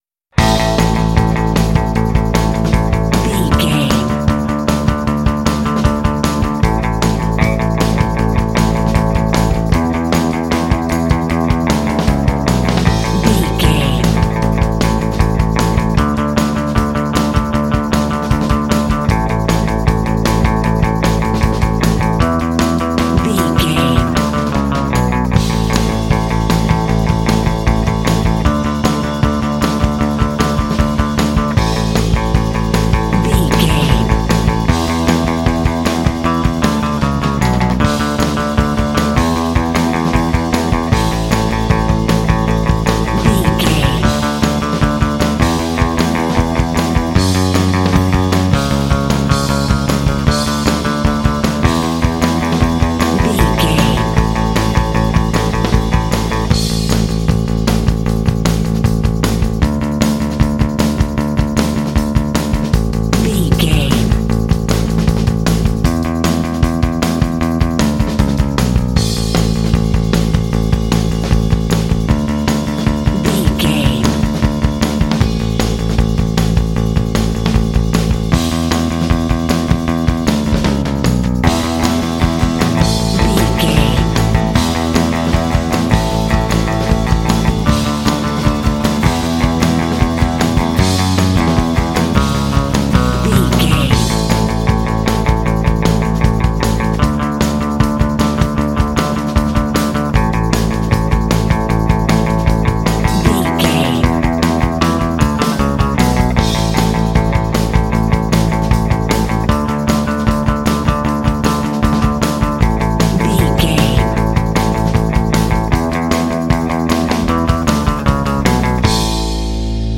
Uplifting
Ionian/Major
energetic
joyful
bass guitar
drums
indie
classic rock
alternative rock